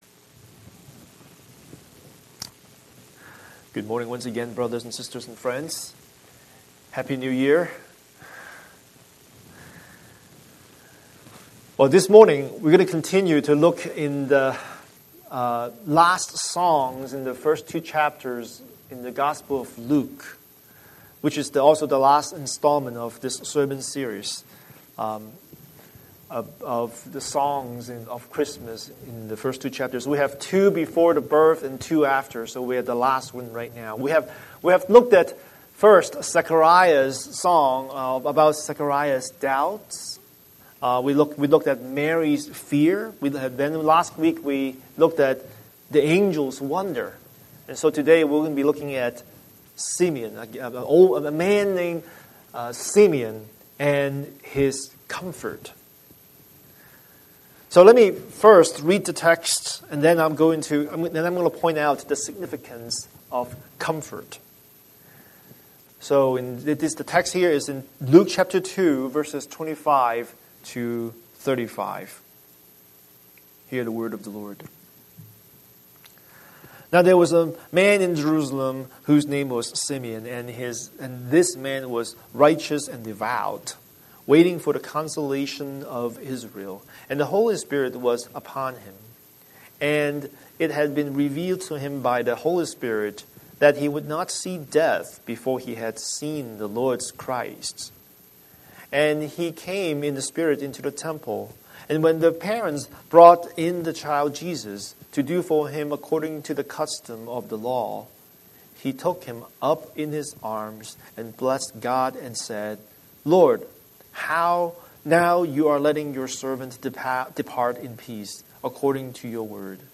Scripture: Luke 2:25-35 Series: Sunday Sermon